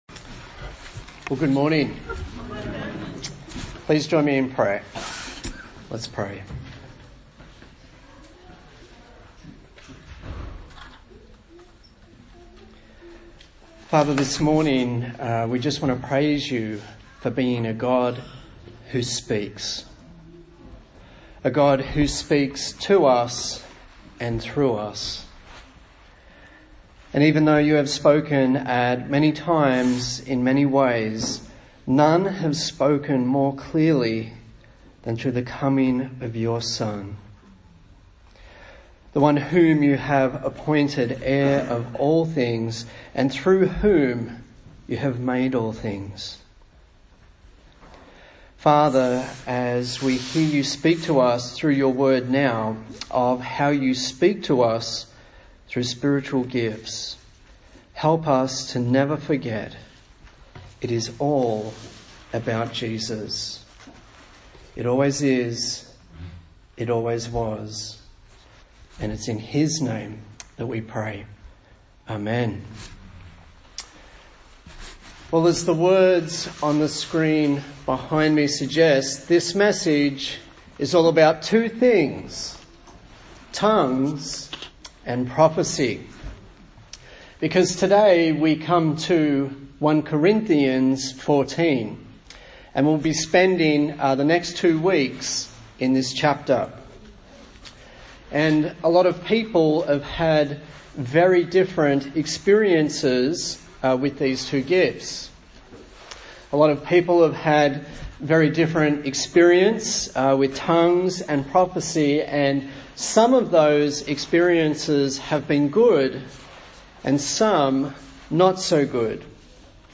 A sermon in the series on the book of 1 Corinthians
Service Type: Sunday Morning